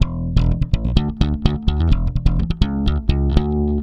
Index of /90_sSampleCDs/Best Service ProSamples vol.48 - Disco Fever [AKAI] 1CD/Partition D/BASS-SLAPPED